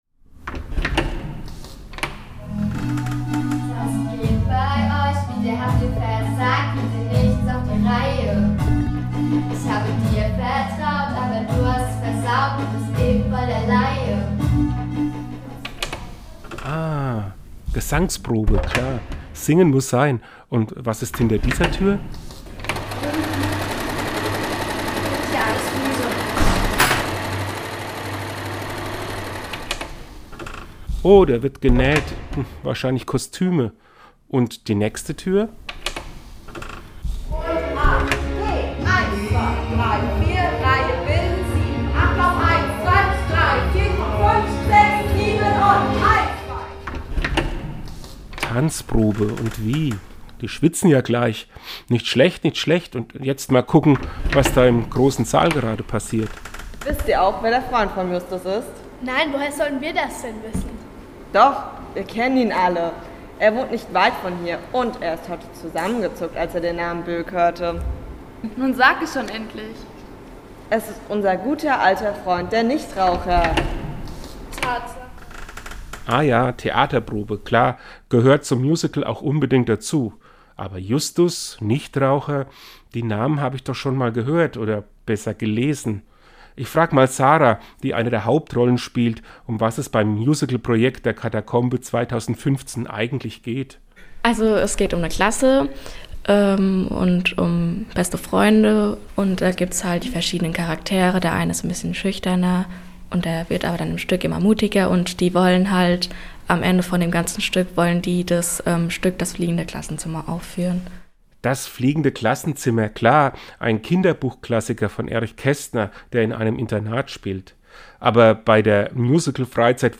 Seinen Radiobeitrag finden Sie unten als Download!